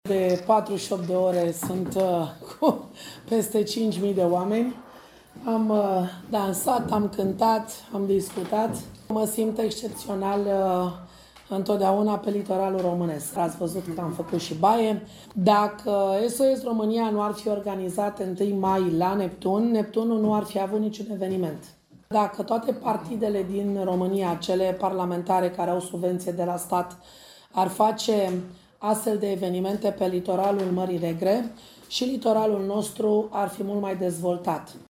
Astăzi, la Constanța, Diana Șoșoacă a susținut o conferință de presă, alături de parlamentarii filialei de la malul mării.